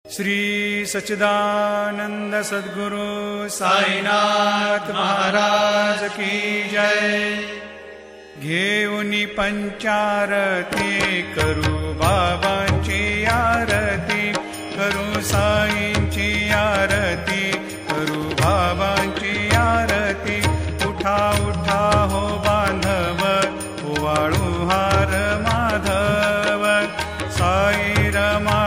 Indian musical work